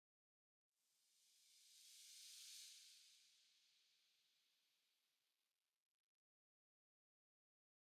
Minecraft Version Minecraft Version snapshot Latest Release | Latest Snapshot snapshot / assets / minecraft / sounds / block / sand / sand4.ogg Compare With Compare With Latest Release | Latest Snapshot
sand4.ogg